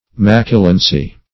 Macilency \Mac"i*len*cy\, n.
macilency.mp3